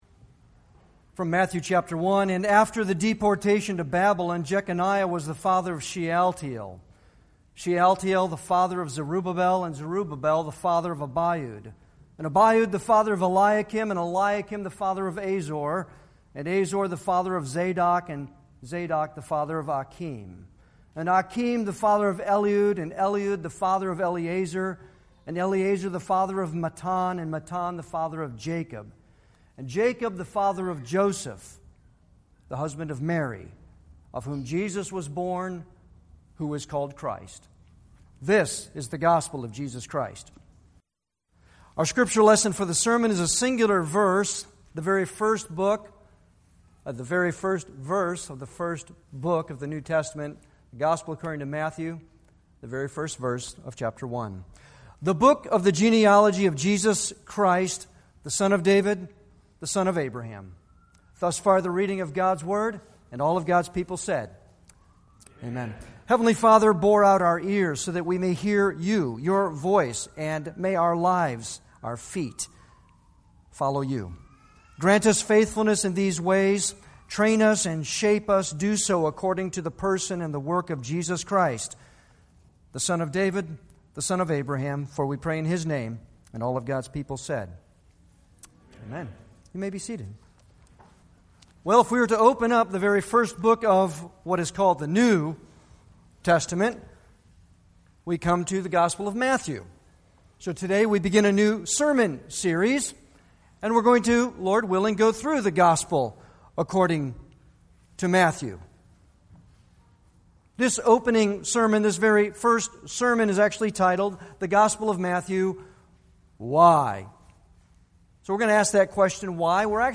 Sermons
Service Type: Sunday worship